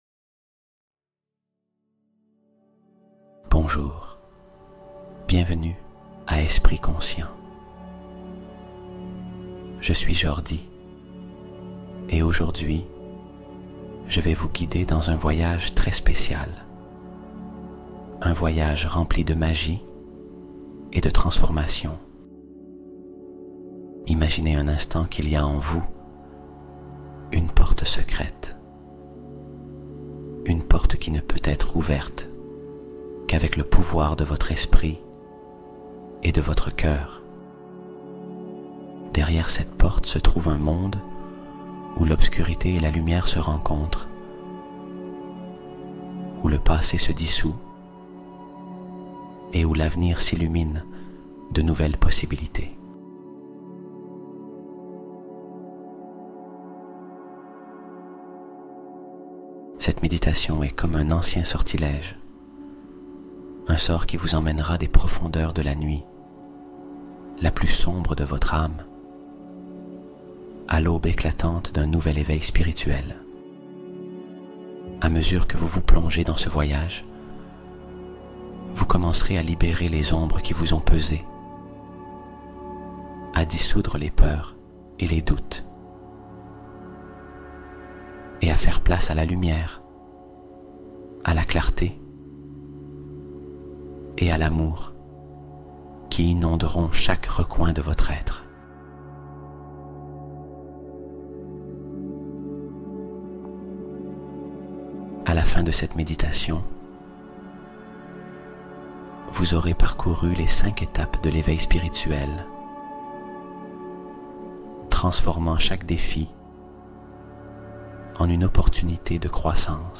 Reçois Les Réponses Pendant Ton Sommeil : Hypnose Spéciale Pour Résoudre Tes Problèmes en Dormant